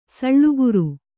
♪ saḷḷuguru